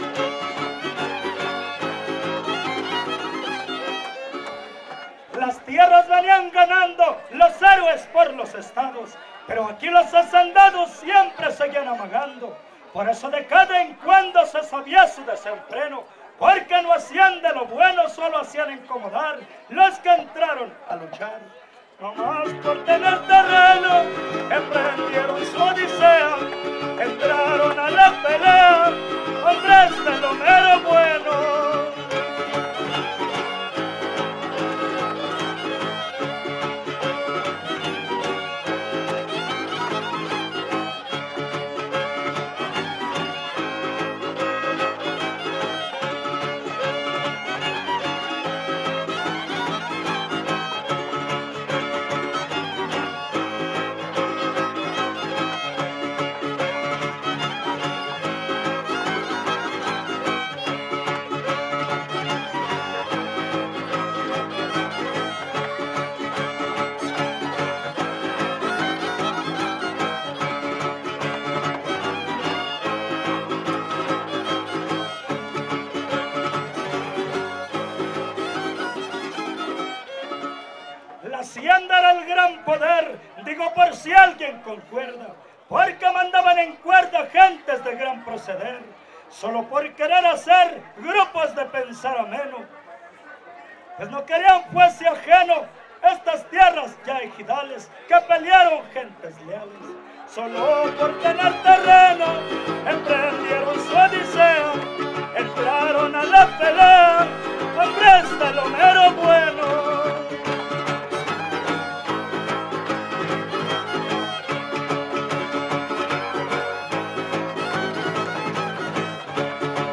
Cárdenas, San Luis Potosí
Décima Poesía popular
Huapango arribeño
No identificado (violín primero)
Vihuela Violín Guitarra